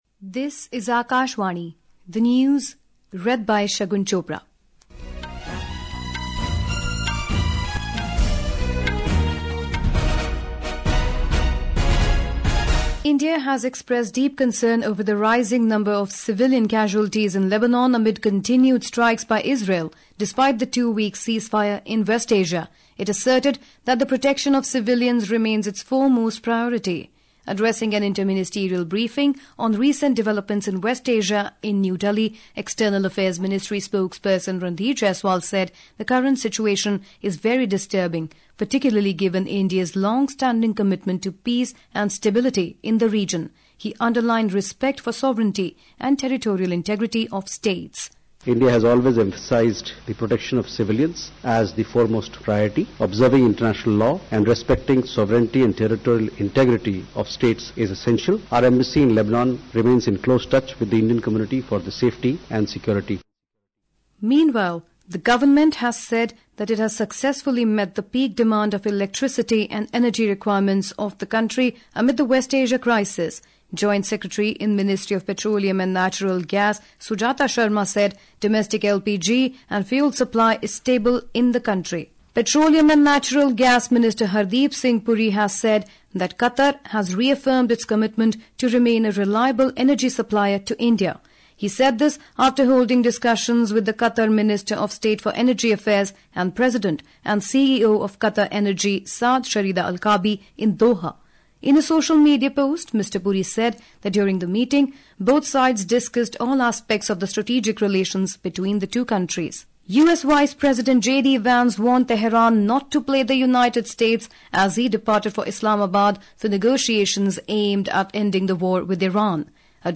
Hourly News